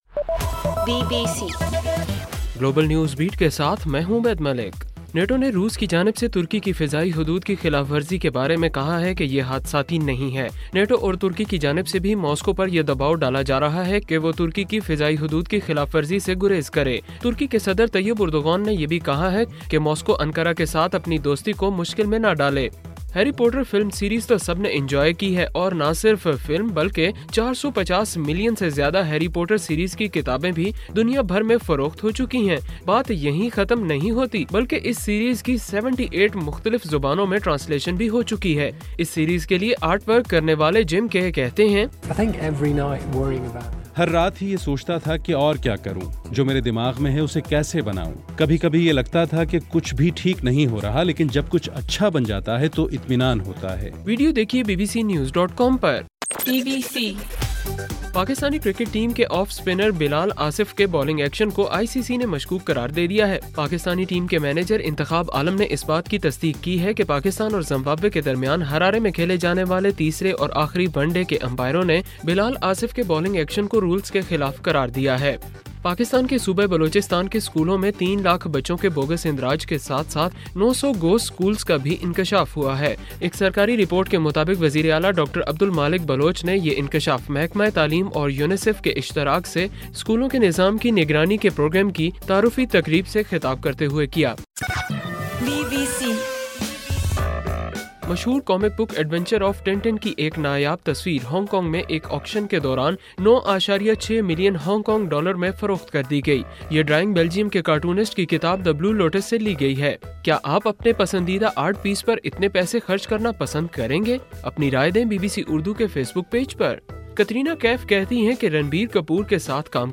اکتوبر 6: رات 8 بجے کا گلوبل نیوز بیٹ بُلیٹن